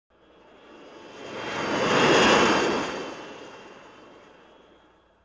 BSG FX - Viper - Pass by 01
BSG_FX_-_Viper_-_Pass_by_01.wav